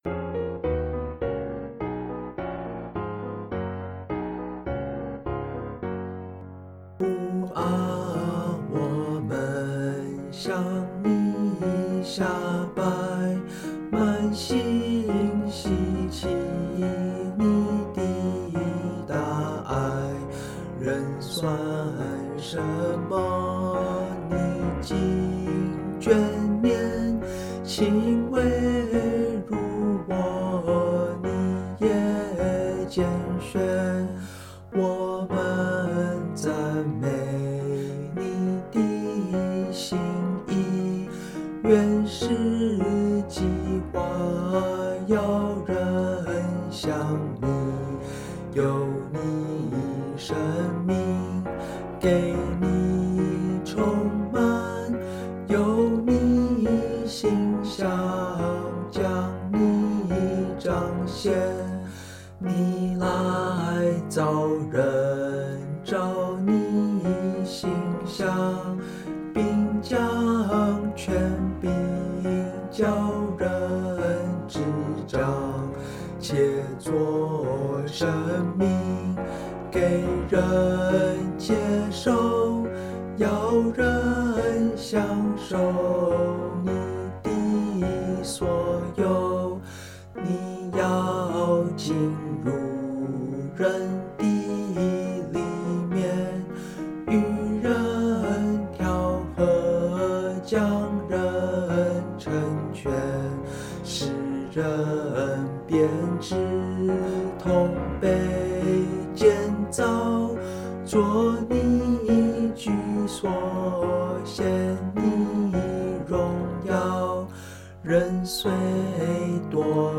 Ab Major